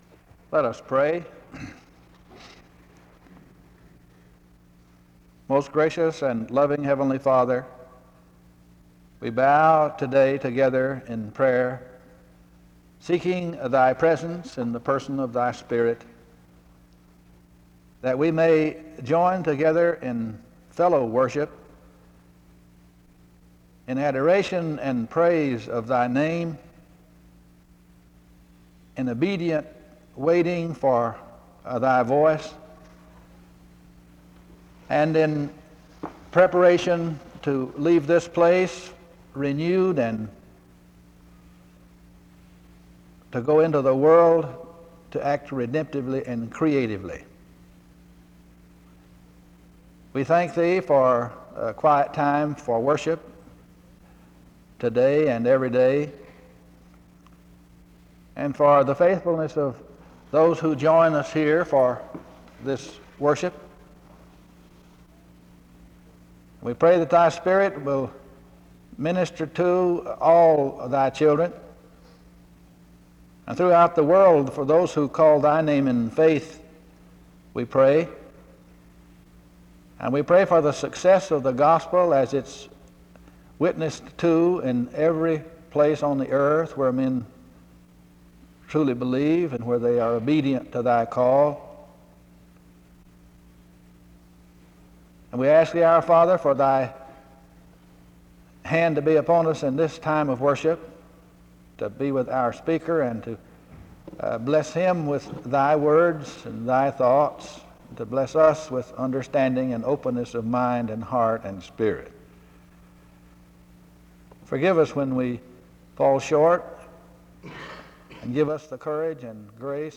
The service begins with a prayer (0:00-2:18).
The service continues with a period of singing (4:04-8:00).
The service concludes in song (20:49-21:29).